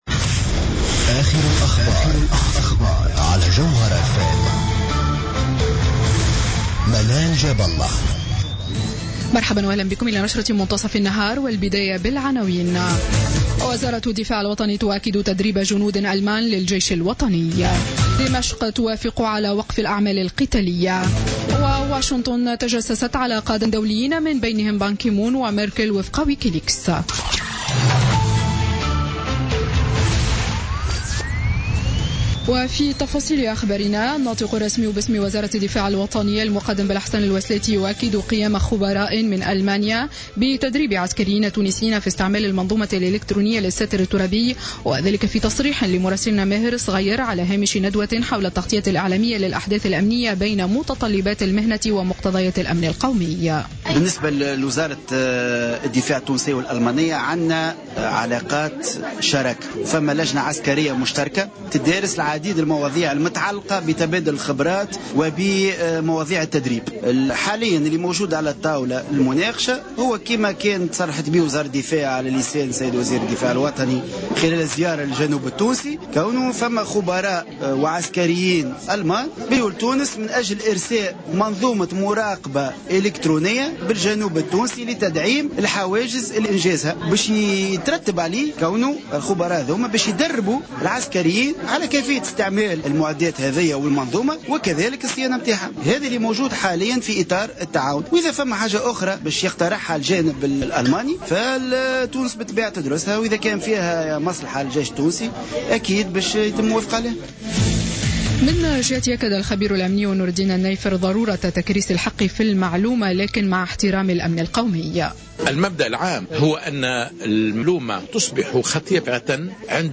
نشرة أخبار منتصف النهار ليوم الثلاثاء 23 فيفري 2016